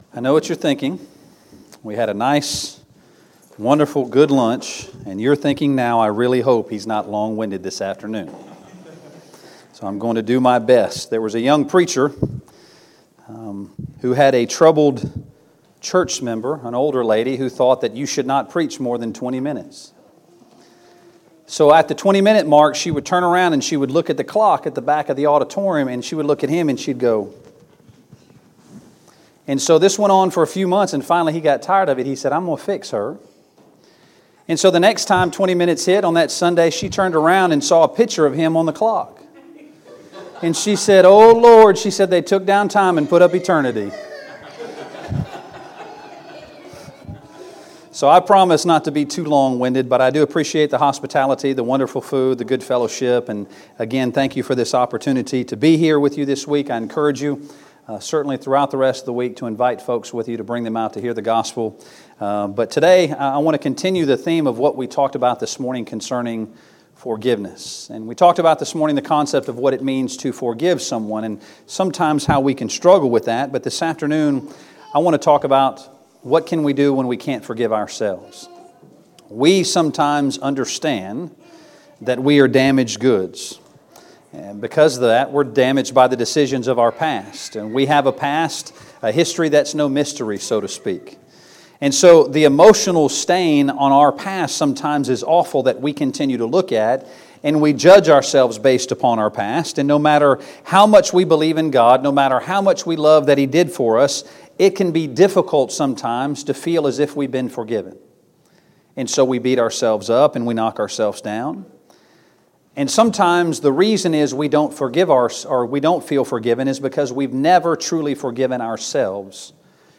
Series: 2019 Spring Gospel Meeting Service Type: Gospel Meeting Preacher